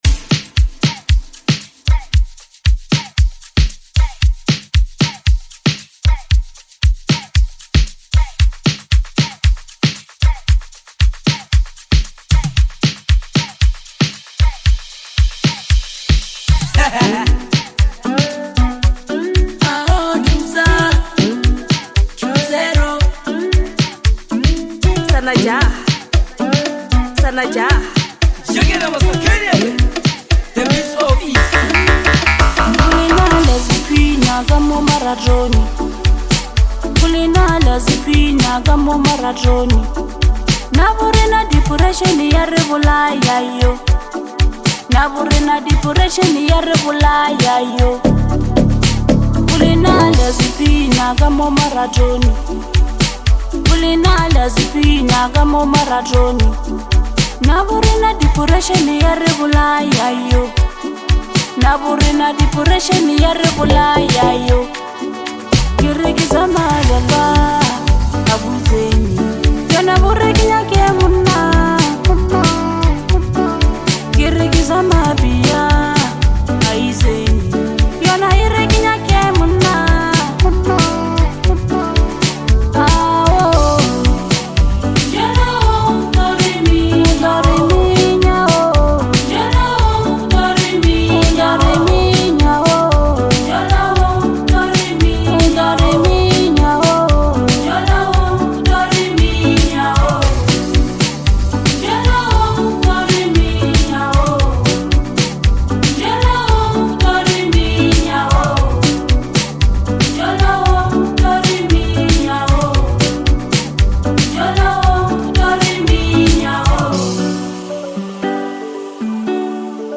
Lekompo
Genre: bolo music.